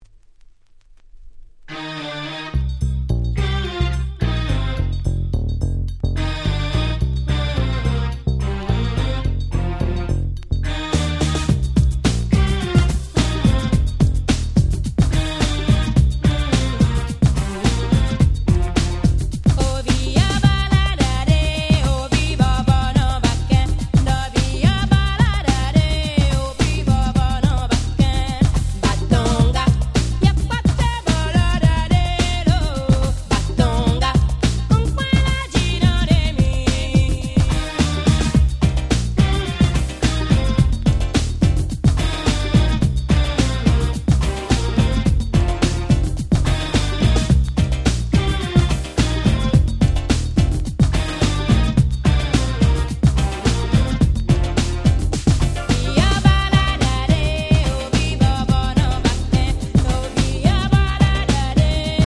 91' Very Nice Afrobeats !!
エキゾチックなメロディーと歌がもう堪りません！！